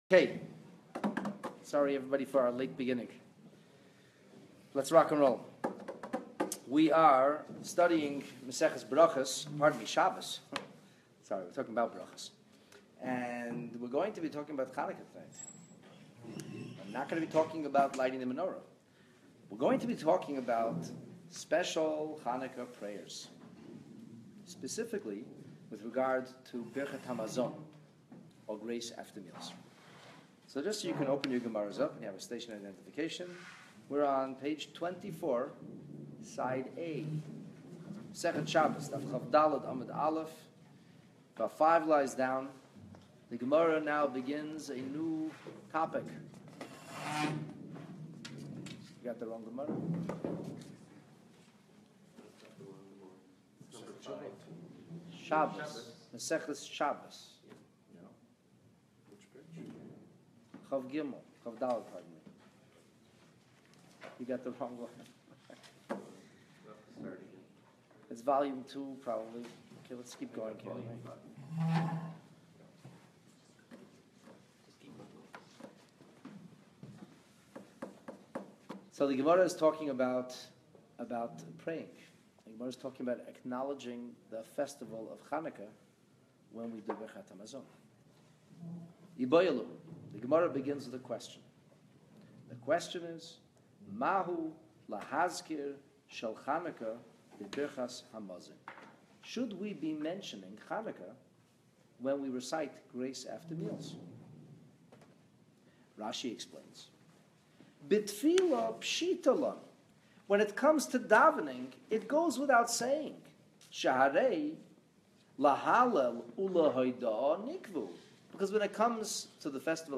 A Torah class